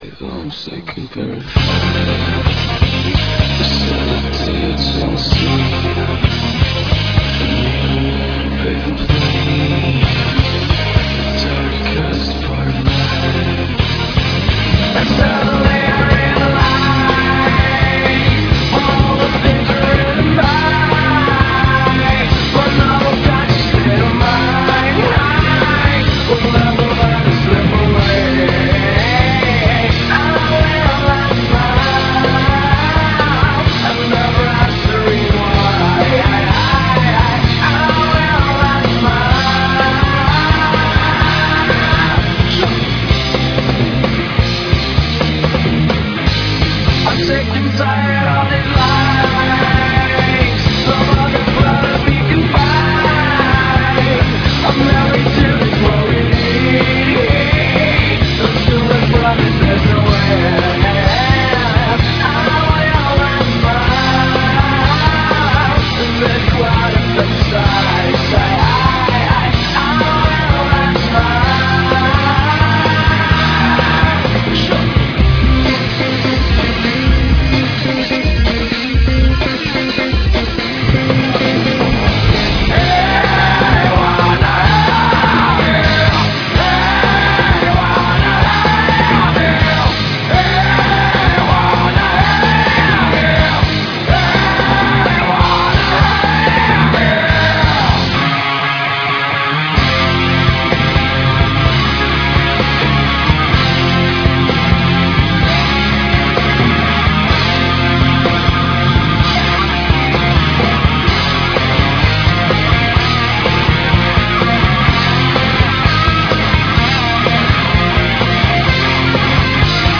A slow-burner.. and then it kicks right in your face.